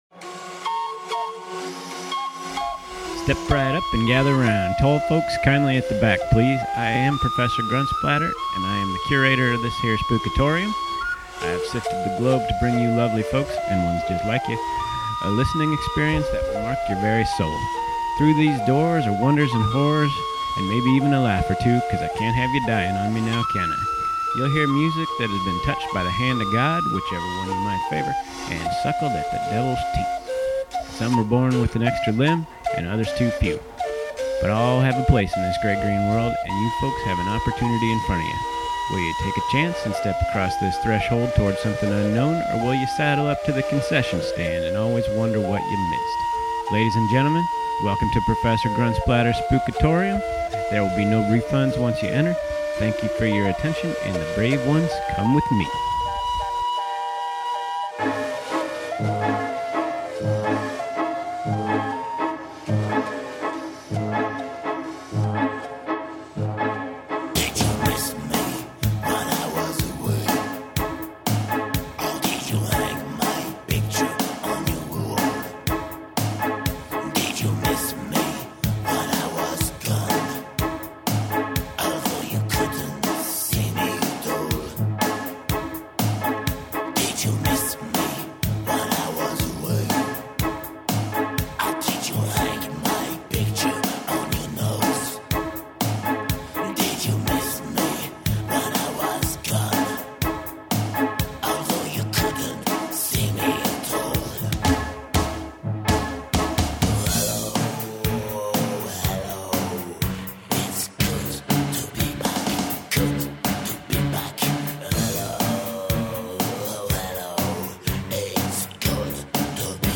Background Sounds